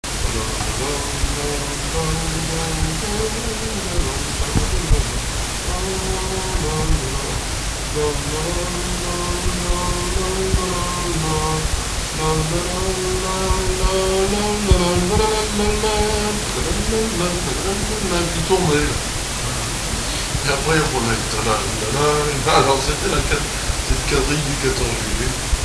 danse : quadrille
Pièce musicale inédite